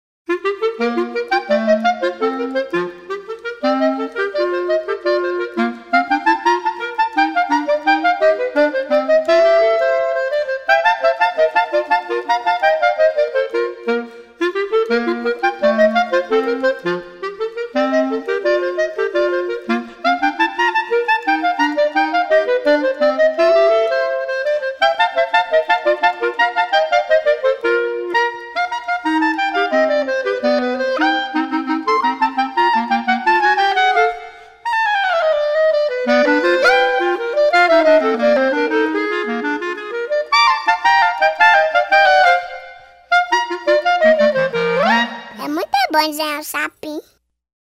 2023   00:47:00   Faixa:     Instrumental